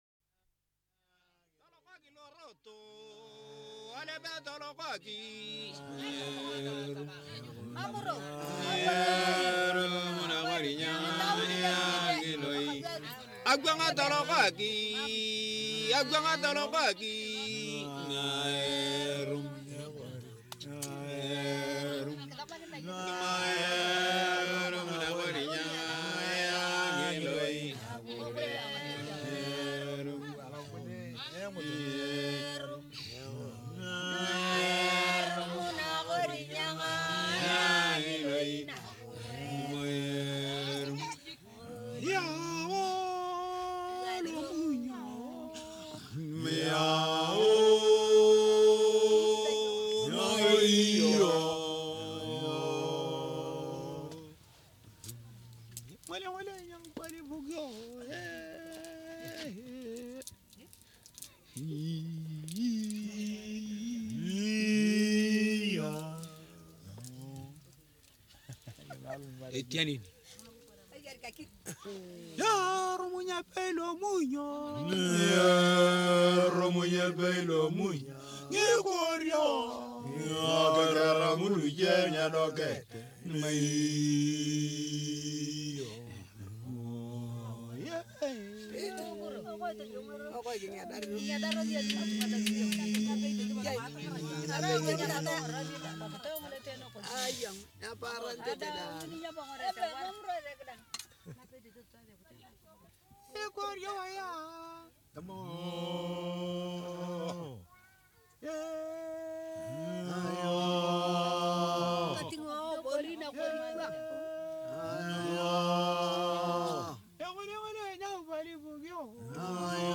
CANTI E MUSICHE CERIMONIALI DA UGANDA, KENYA E TANZANIA